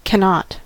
cannot: Wikimedia Commons US English Pronunciations
En-us-cannot.WAV